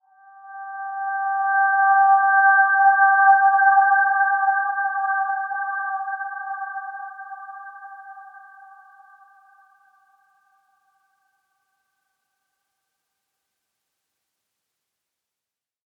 Dreamy-Fifths-G5-p.wav